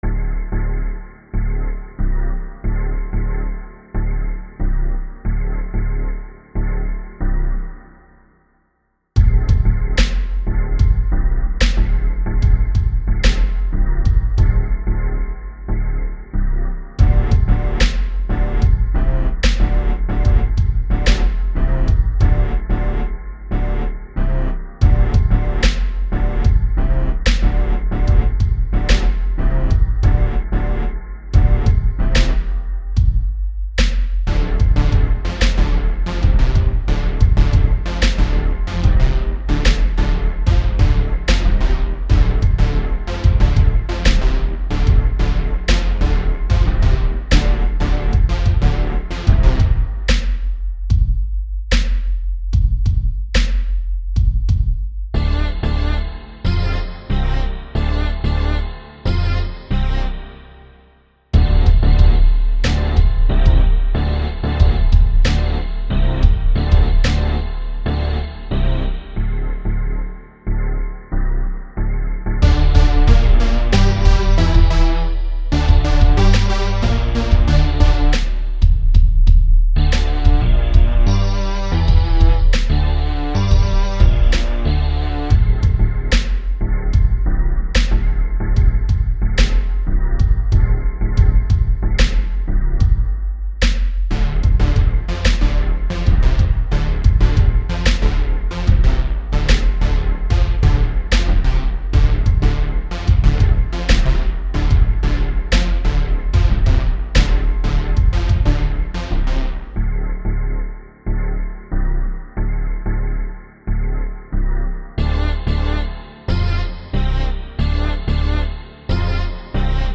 Répertoire de fichiers Racine Musiques Perso Rap Nom de fichier Type Taille Modifié le ..